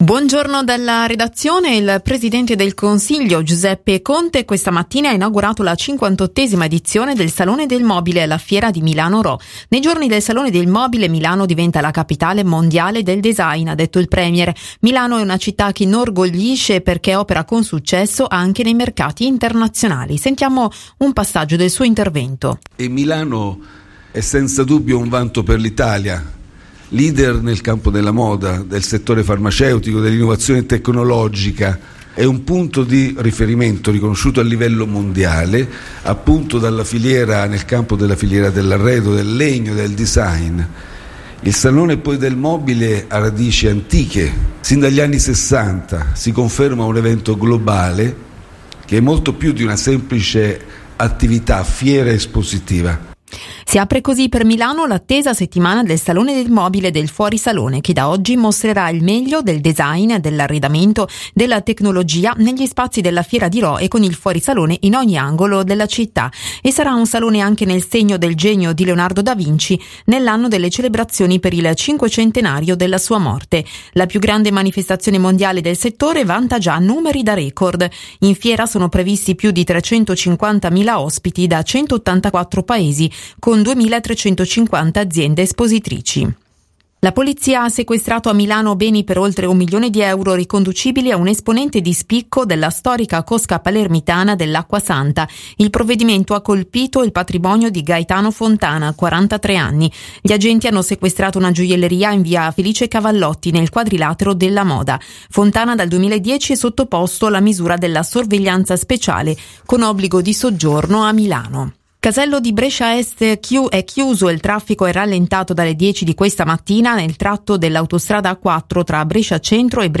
Radio Lombardia, Giornale Radio delle 12.28